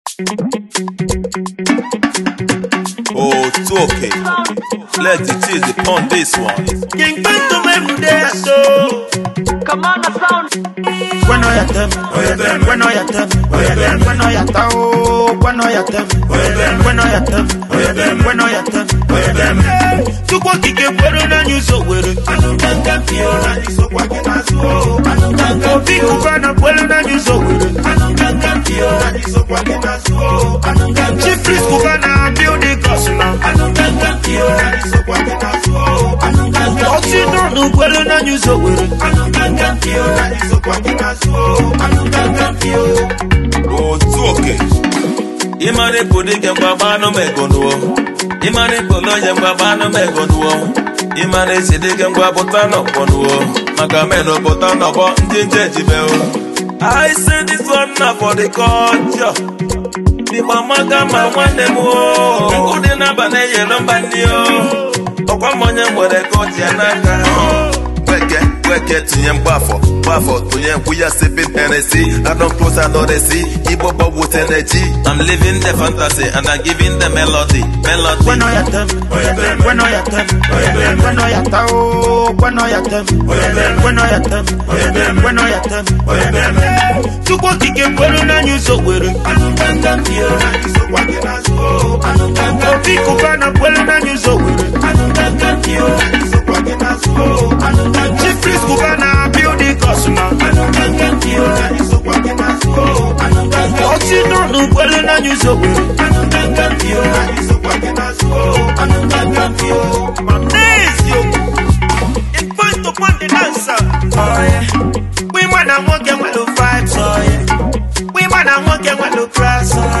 it's a hip life genre with an African fusion
a hiplife song